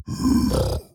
Minecraft Version Minecraft Version 1.21.5 Latest Release | Latest Snapshot 1.21.5 / assets / minecraft / sounds / mob / piglin_brute / idle6.ogg Compare With Compare With Latest Release | Latest Snapshot